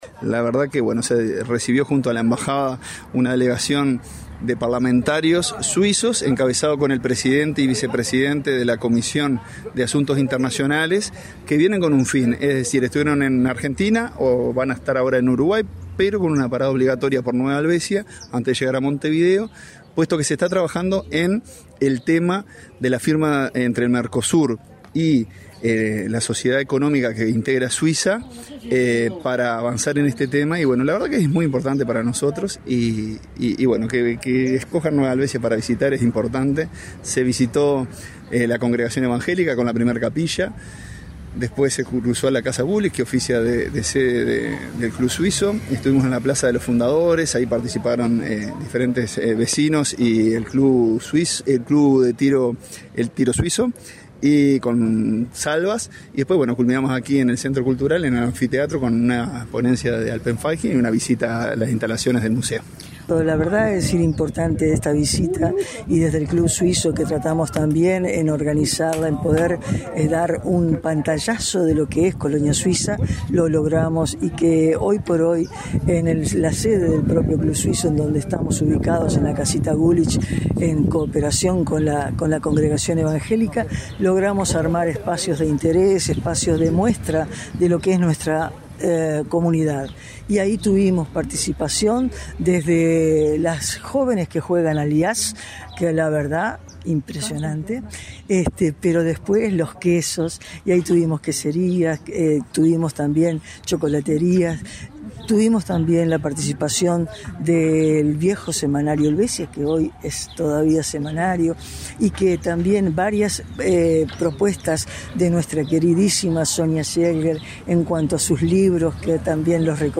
Tras la reunión, escuchamos las palabras del alcalde de Nueva Helvecia, Marcelo Alonso